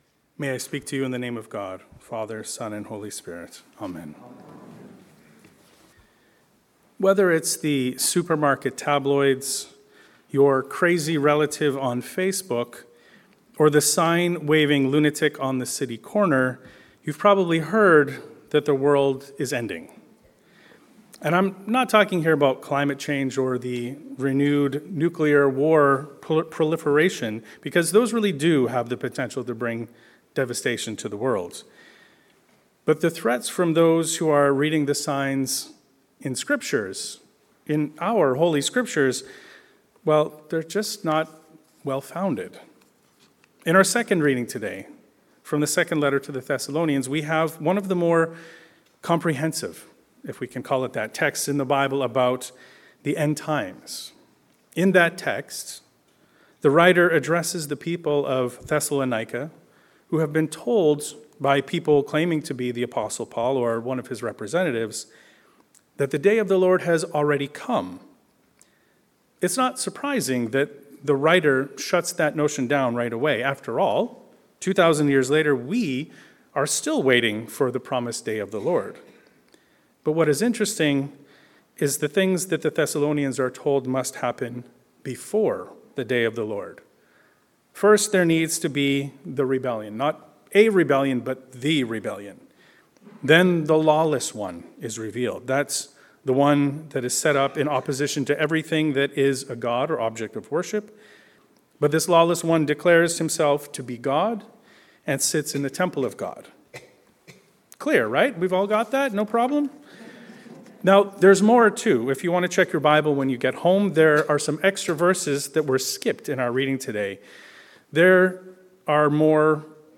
The Promise of Peace. A Sermon on Haggai 2, 2 Thessalonians 2, and Luke 20.27-38